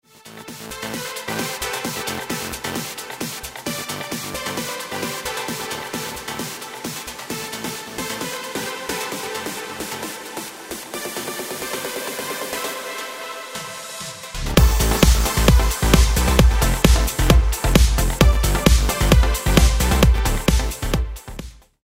3. Rhythmic Tension & Resolution
Macro Example (This is an example of a pitch-rising snare roll leading into the drop, with a syncopated pattern at the end and a tom drum fill, a reverse cymbal, and a crash on the resolution):
Tension-and-Release-Rhythm-Macro.mp3